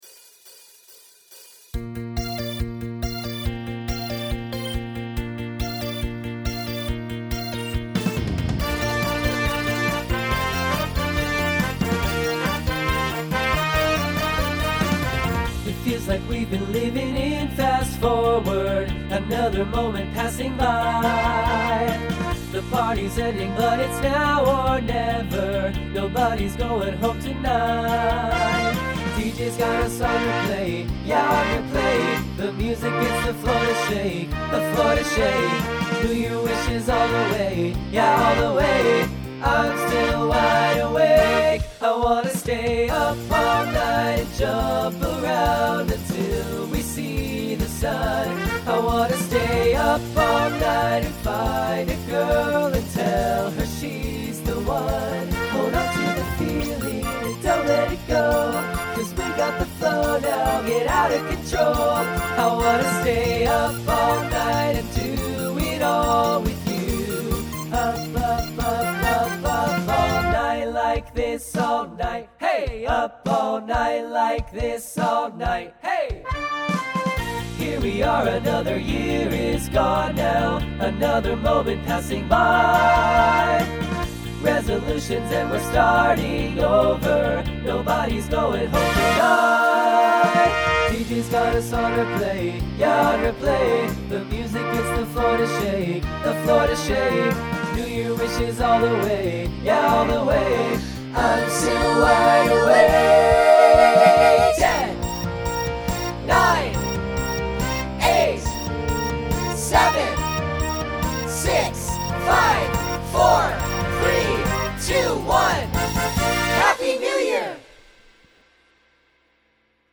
A little bit of SATB at the very end.
Genre Pop/Dance
Voicing TTB